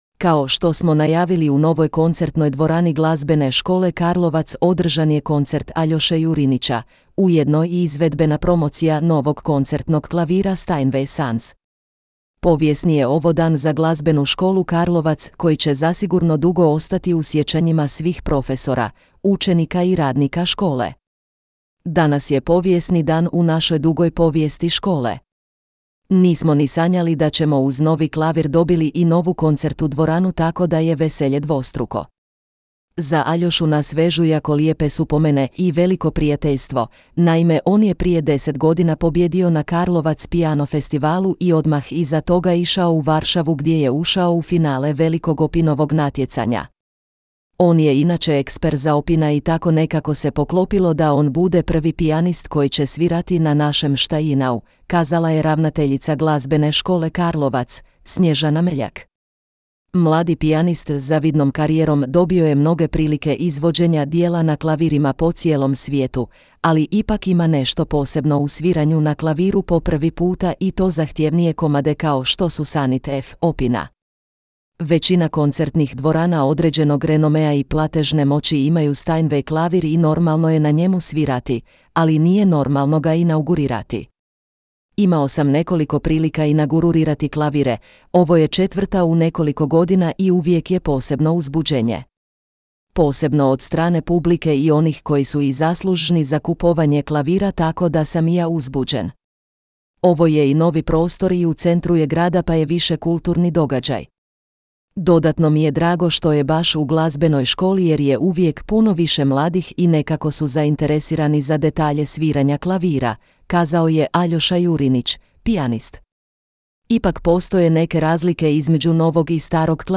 Novi klavir, nova dvorana, talentirani pijanist – prekrasan koncert